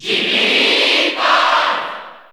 Jigglypuff_Cheer_Italian_SSB4_SSBU.ogg